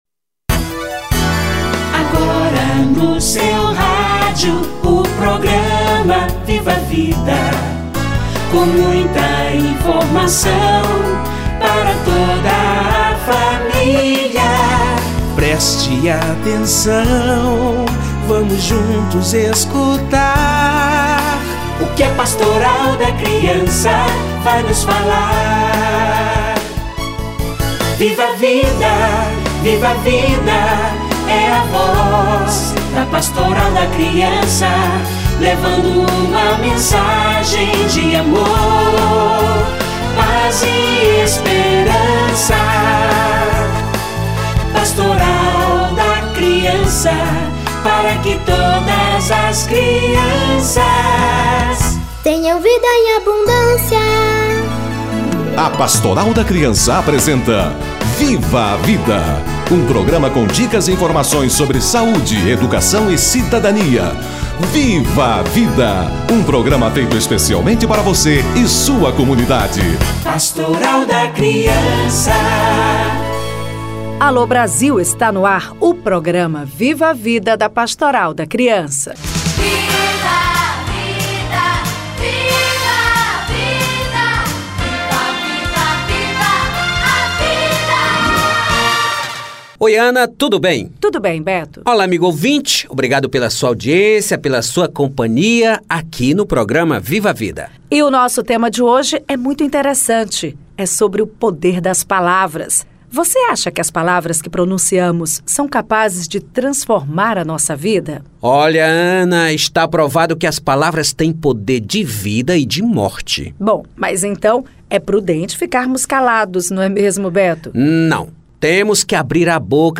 Palavra dos pais - Entrevista